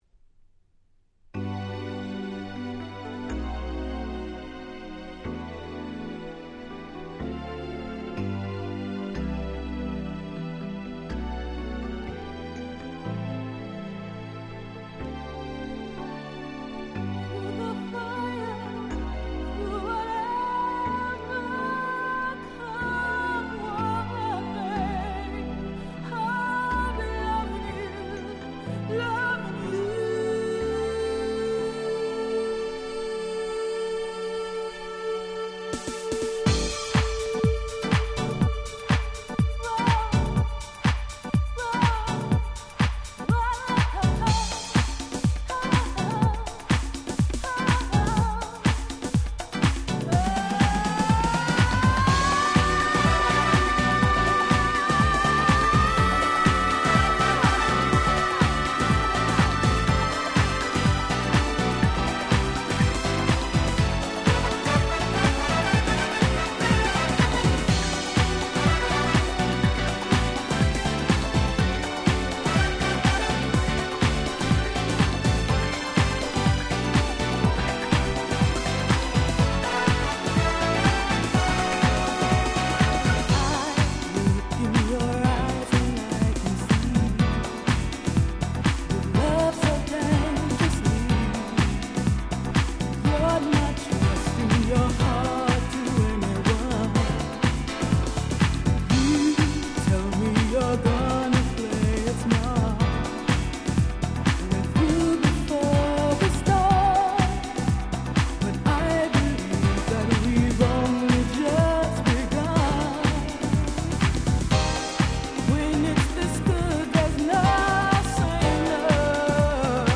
96' Nice Japanese House LP !!